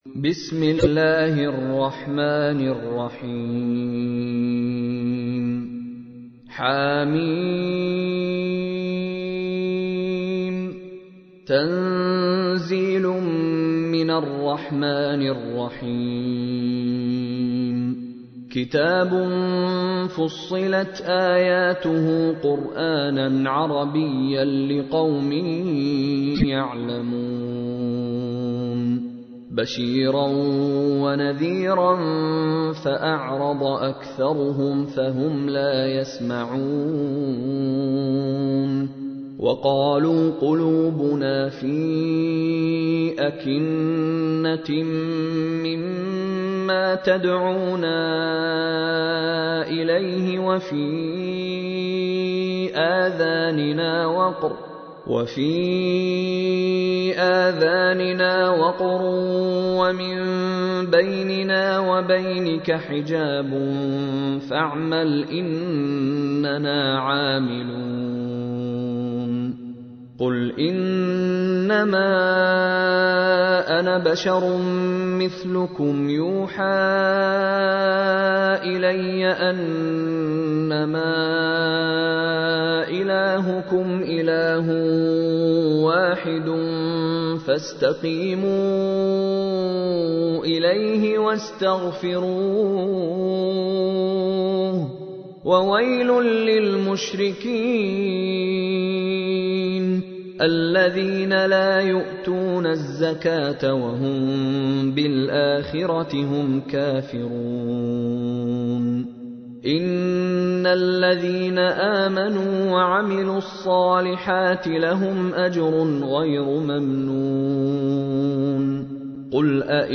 تحميل : 41. سورة فصلت / القارئ مشاري راشد العفاسي / القرآن الكريم / موقع يا حسين